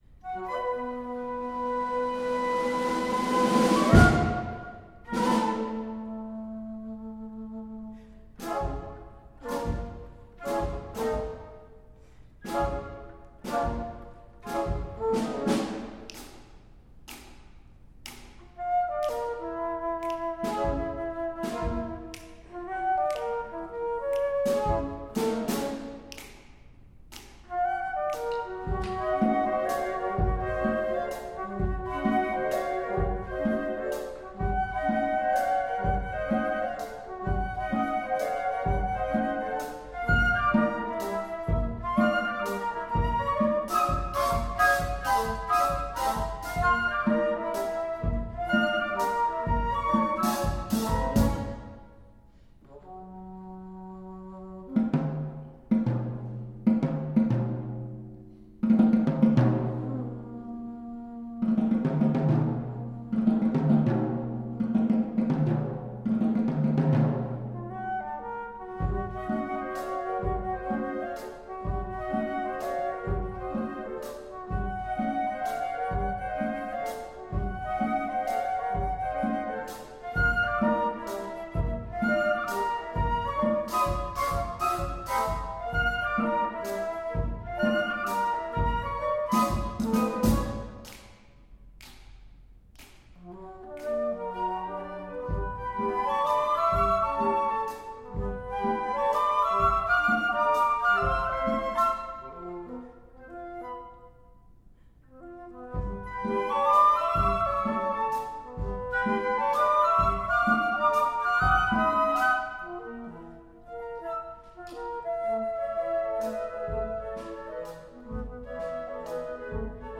Arranged for Flute Quartet